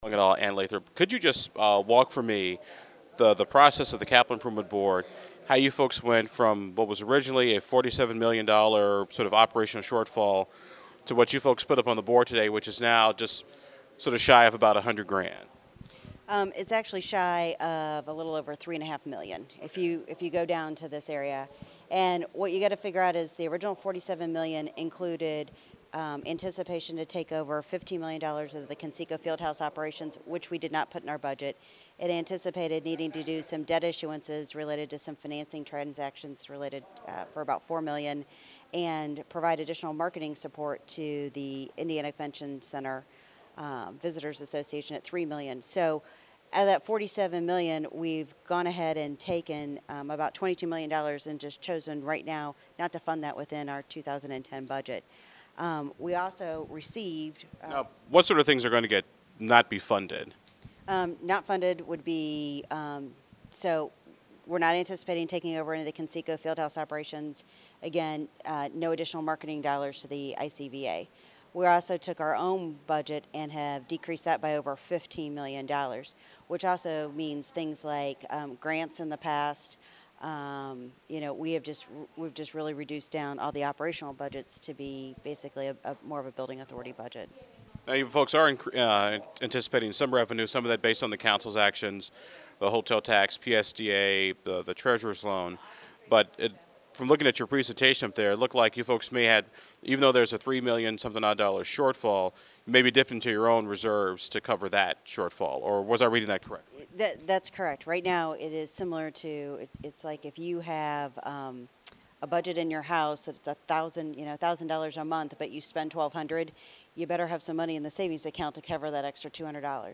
Below is some audio from today’s meeting…